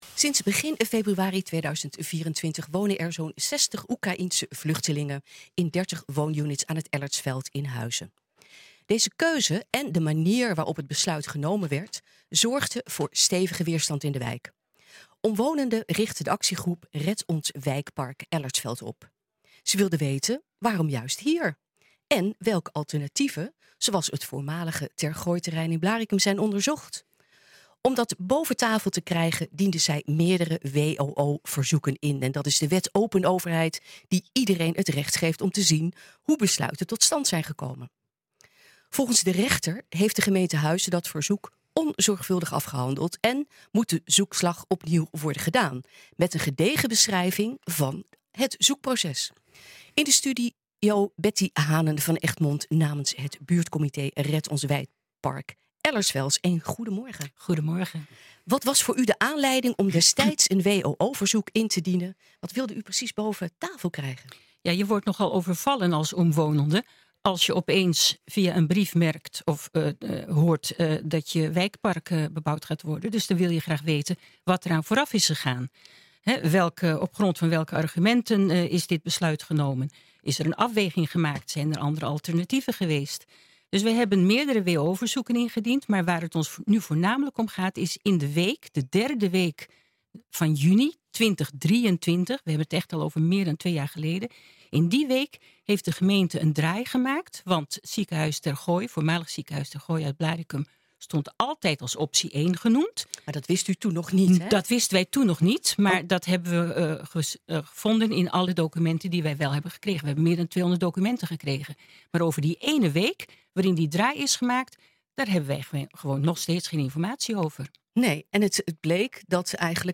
In de studio: